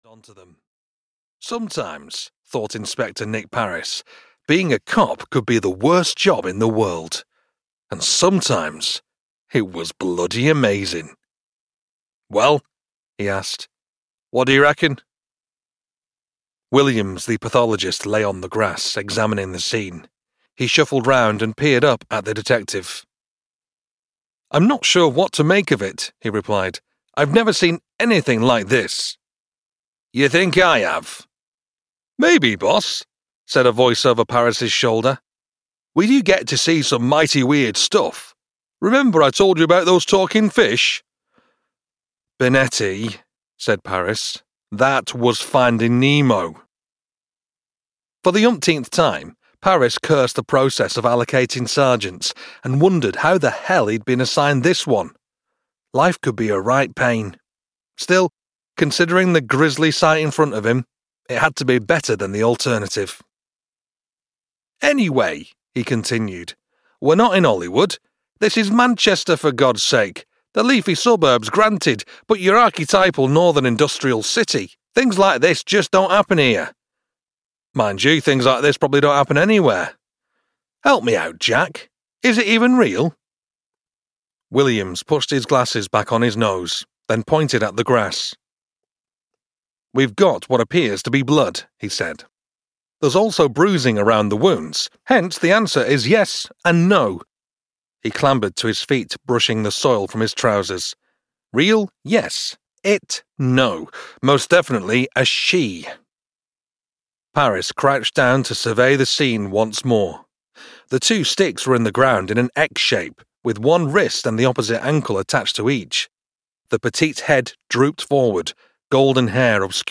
Breaking the Lore (EN) audiokniha
Ukázka z knihy